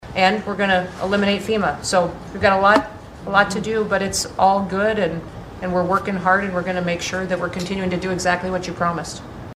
PIERRE, S.D. (SDBA) — Homeland Security Secretary Kristi Noem announced plans to eliminate the Federal Emergency Management Agency during a Cabinet meeting on Monday, sparking controversy and raising questions about the future of disaster response in the United States.
Noem made the brief statement while discussing various Department of Homeland Security operations.